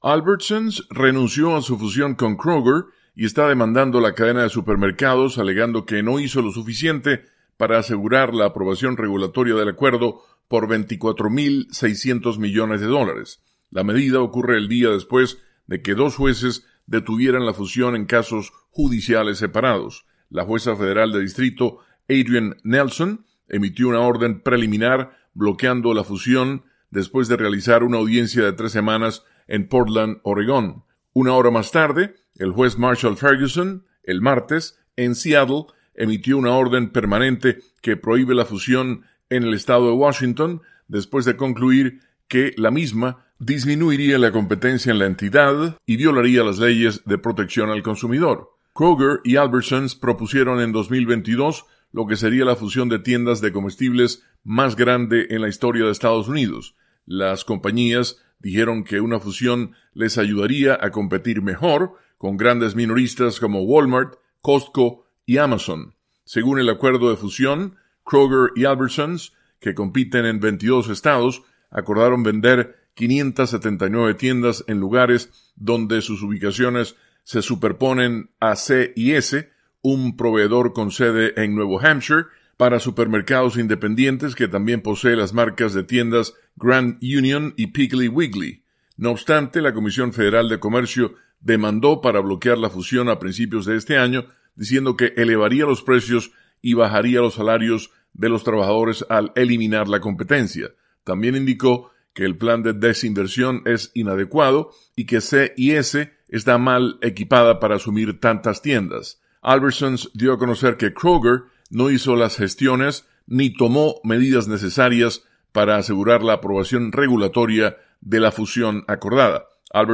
desde la Voz de América, en Washington.